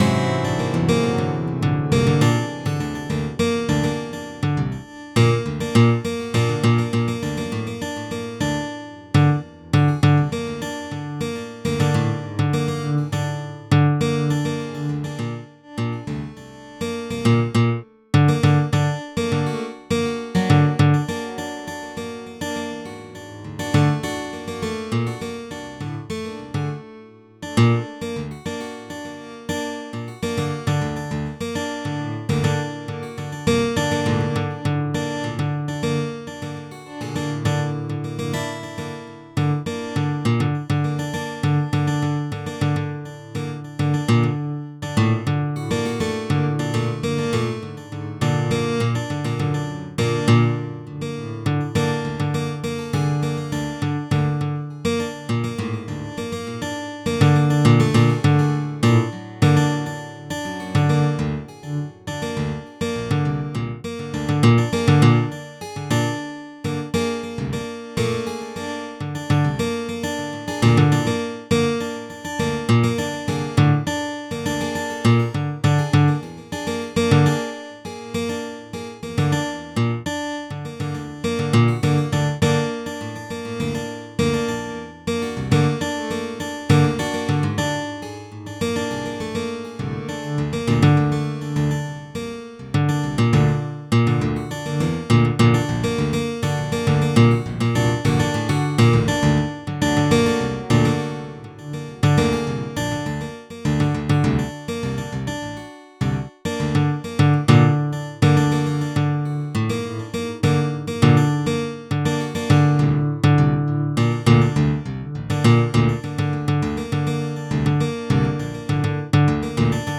エレクトリック・アコースティック・ギターを使用しての
即興的タッピング演奏の仮想ライブ。
with both hands tapping technique
this is the multi-layered simulated guitars album.